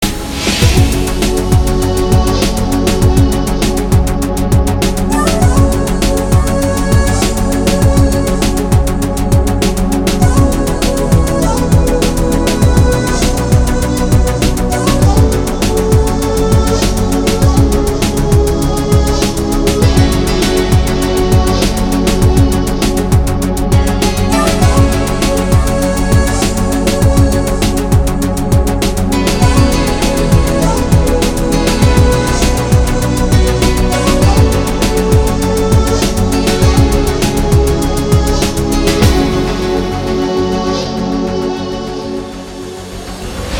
Танцевальные
Стиль: new age